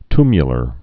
(tmyə-lər, ty-)